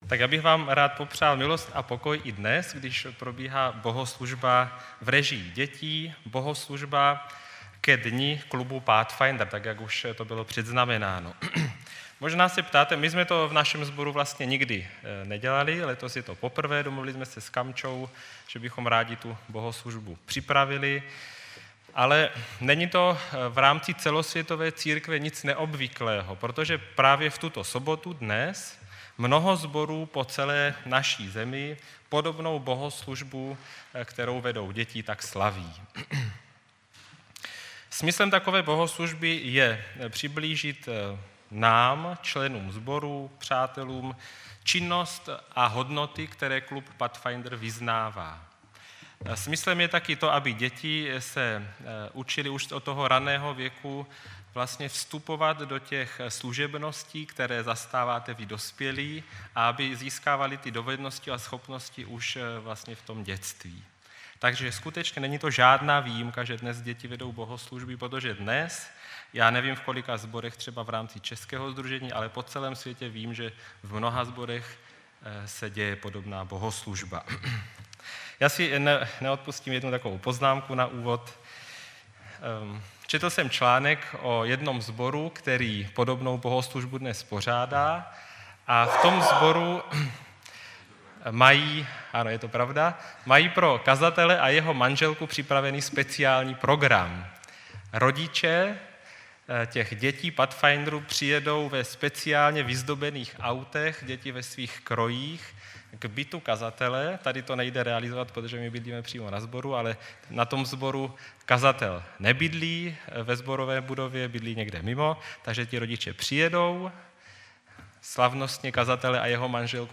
BOHOSLUŽBA KE DNI KLUBU PATHFINDER